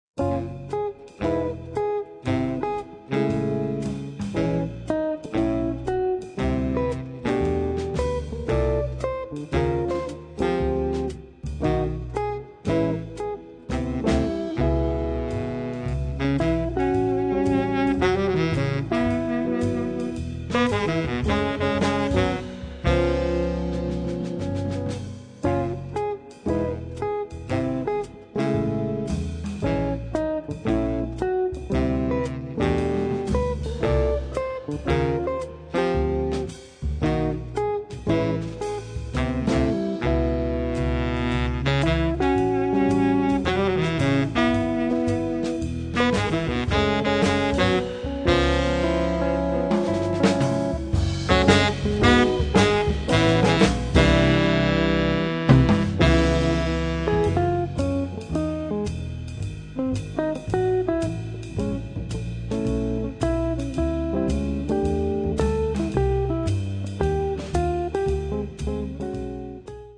Chitarra
Sax Baritono
Contrabbasso e Basso Elettrico
Batteria